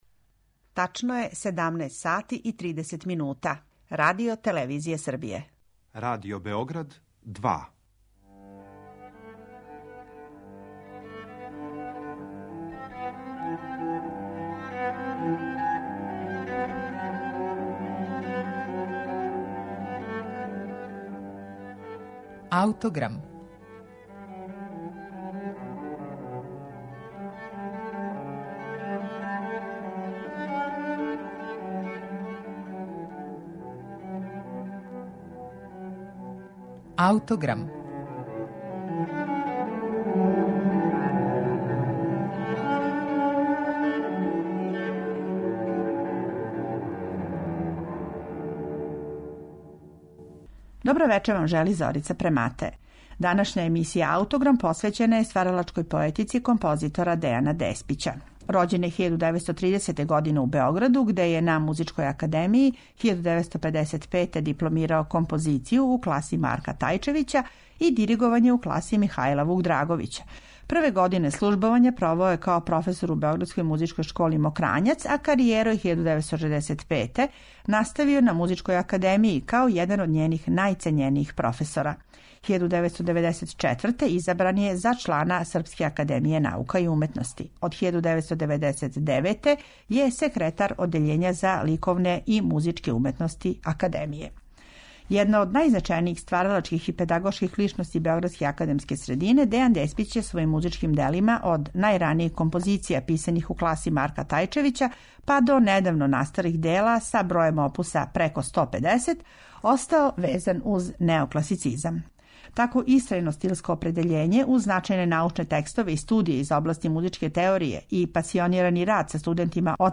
На архивском снимку забележеном 1967.године